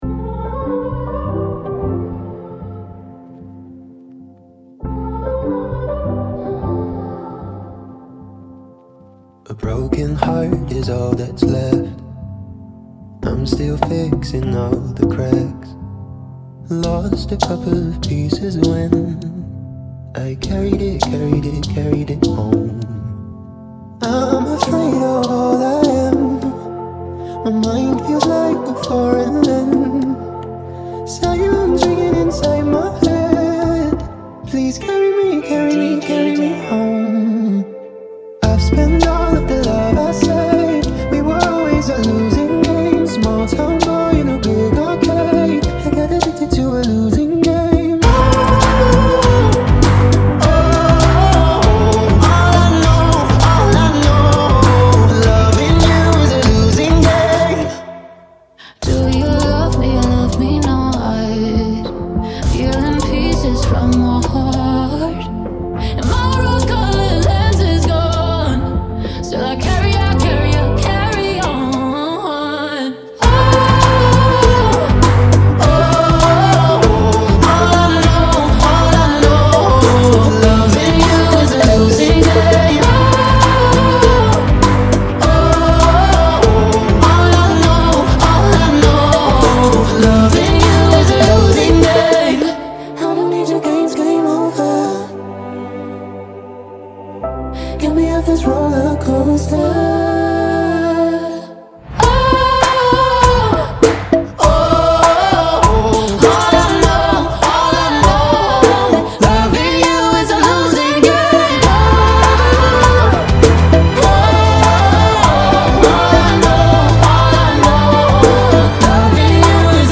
100 BPM
Genre: Bachata Remix